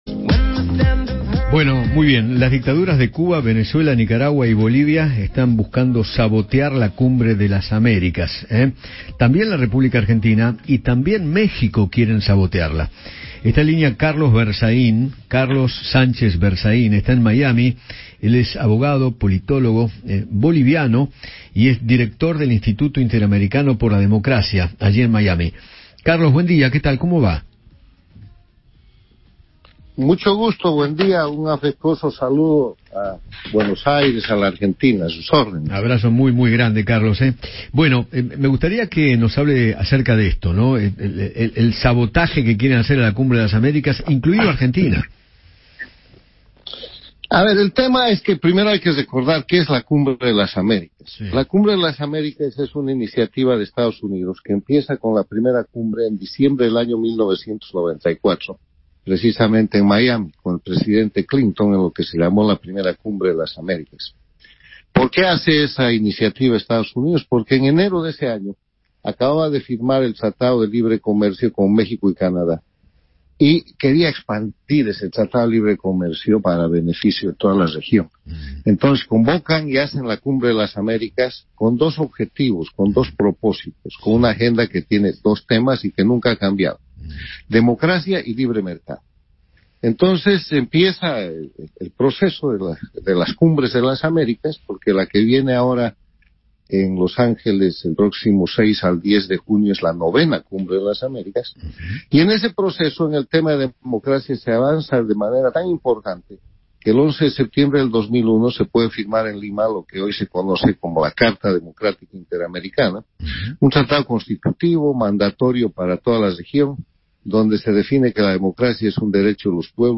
Carlos Sánchez Berzaín, director del Instituto Interamericano por la Democracia y ex ministro boliviano, conversó con Eduardo Feinmann sobre la coyuntura política de los países latinoamericanos, a pocos días de la Cumbre de las Américas.